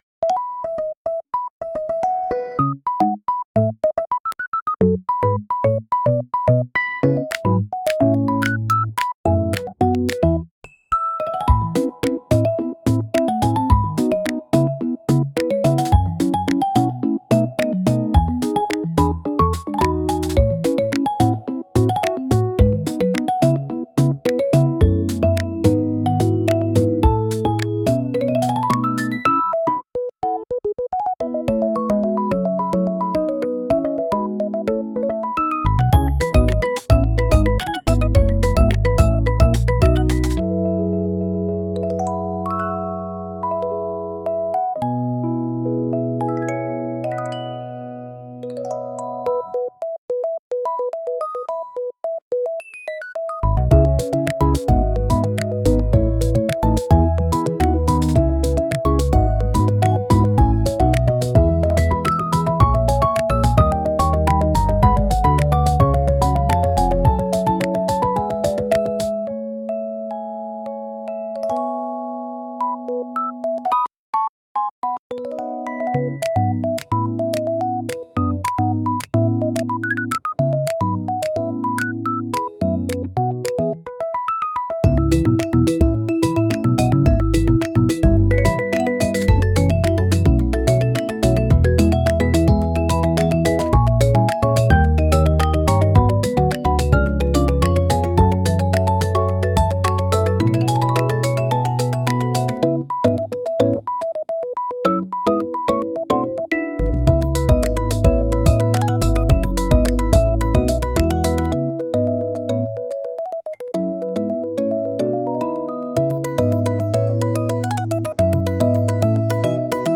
SUNO AI を使用して制作しています
リズミカルなトイBGM